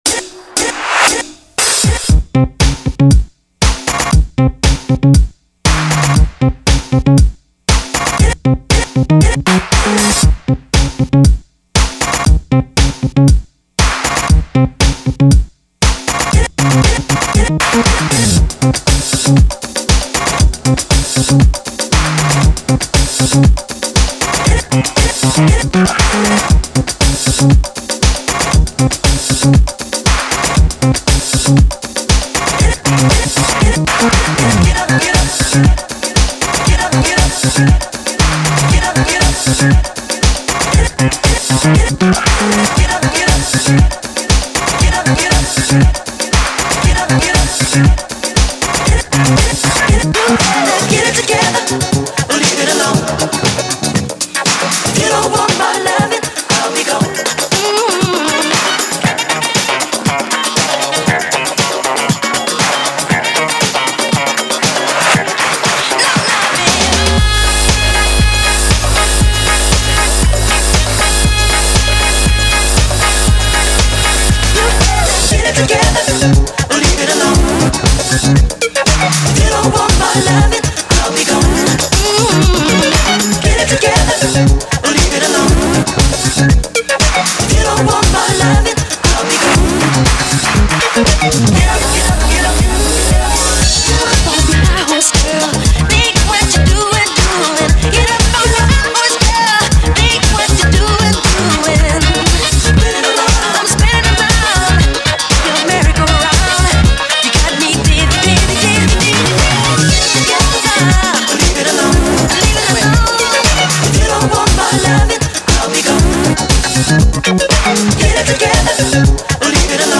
remixes